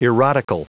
Prononciation du mot : erotical
erotical.wav